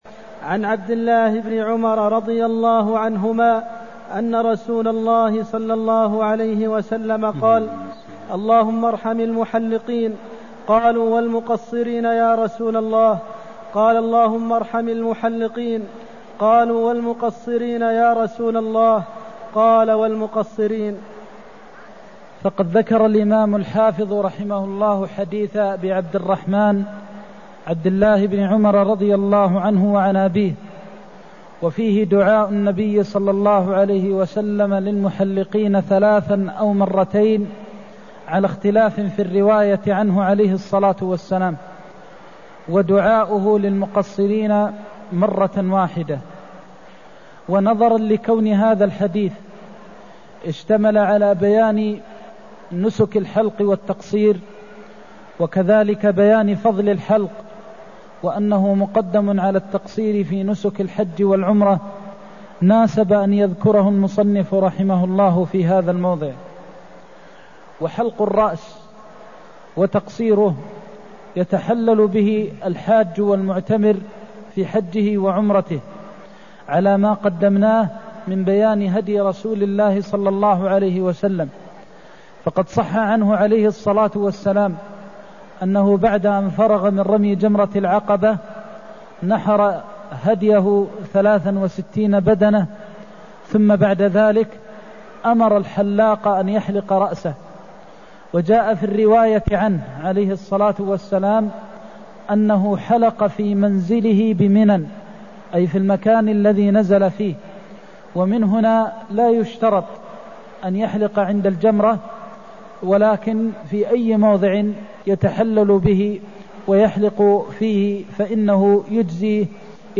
المكان: المسجد النبوي الشيخ: فضيلة الشيخ د. محمد بن محمد المختار فضيلة الشيخ د. محمد بن محمد المختار اللهم ارحم المحلقين (236)